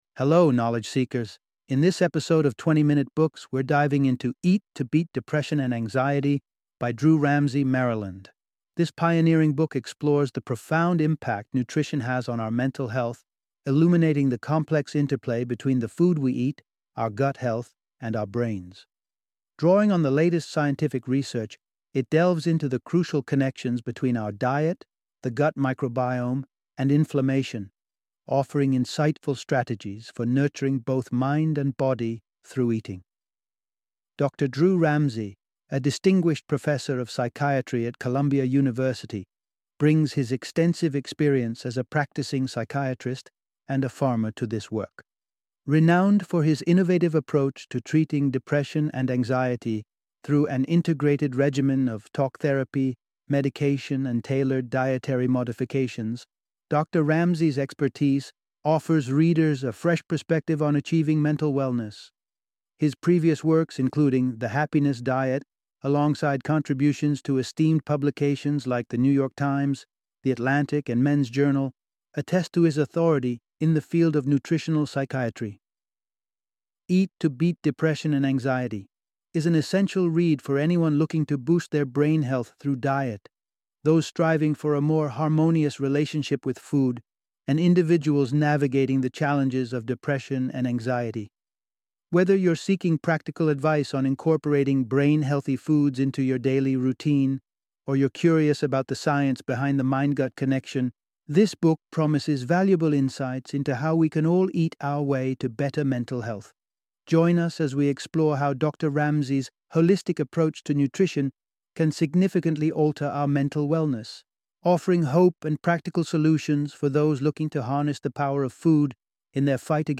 Eat to Beat Depression and Anxiety - Audiobook Summary